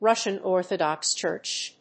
アクセントRússian (Órthodox) Chúrch